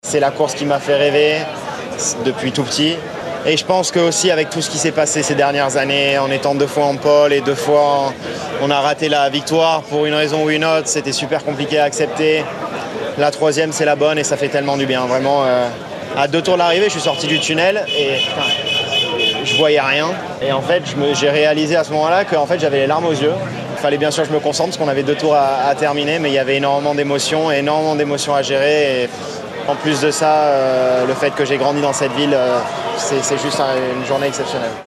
son-charles-leclerc-reac-48441.mp3